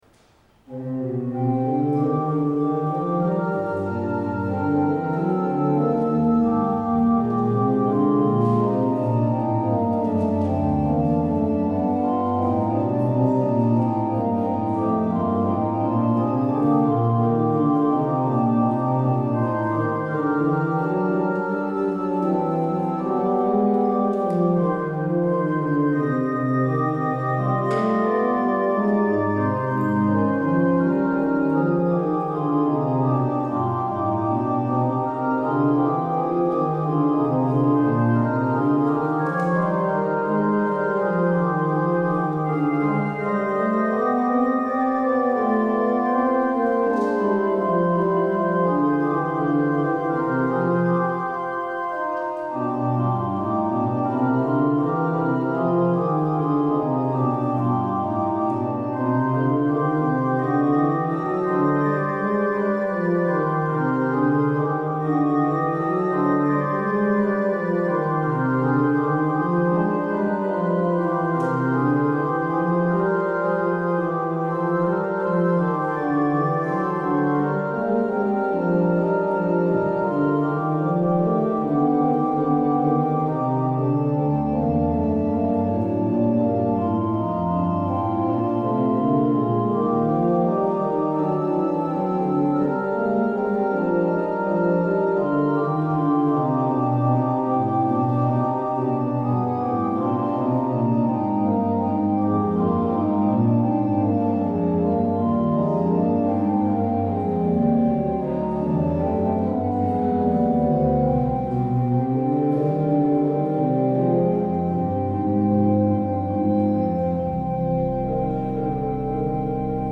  Luister deze kerkdienst hier terug: Alle-Dag-Kerk 23 april 2024 Alle-Dag-Kerk https